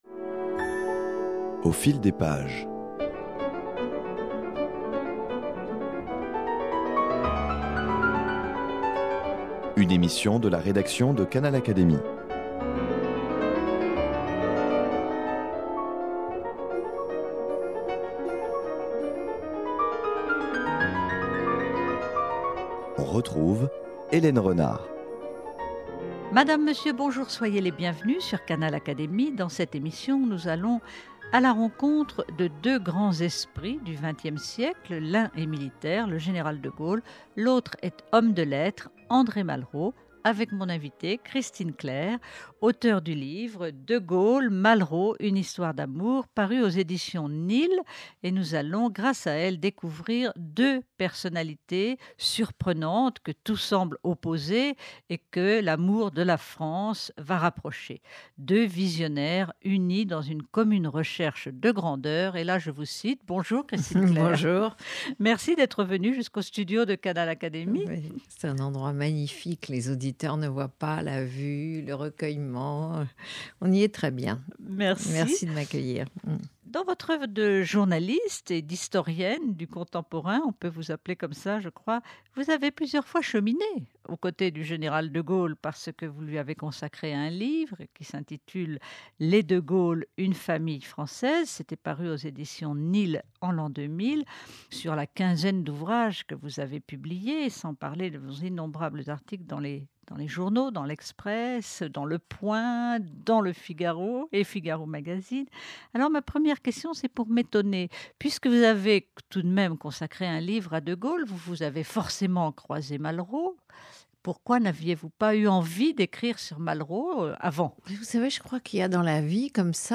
Entre De Gaulle et Malraux, durant vingt cinq années, ce fut une histoire de fascination réciproque que détaille Christine Clerc en relatant la complicité de ce tandem hors du commun. Elle évoque à la fois ce qui les séparait et ce qui les rapprochait, leur amour de la France, leur idée de la supériorité. Rencontre avec une journaliste historienne du contemporain pour découvrir deux grands esprits du XXe siècle.